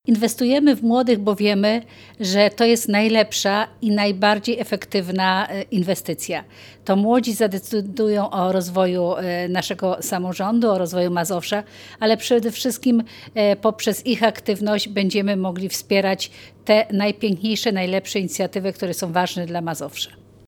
Elżbieta Lanc, członkini zarządu województwa dodaje, że program daje młodzieży szansę na realizację wielu ciekawych inicjatyw.